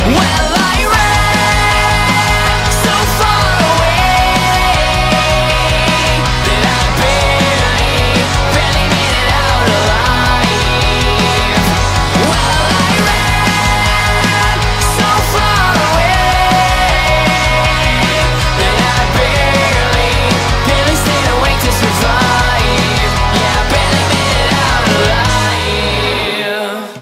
• Качество: 192, Stereo
Драйвовая песня
от молодой рок-группы